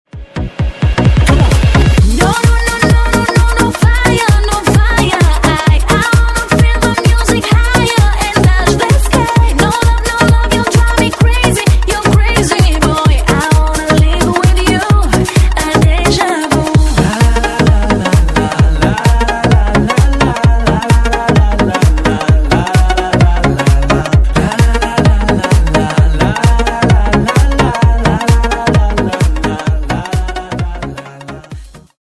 Певица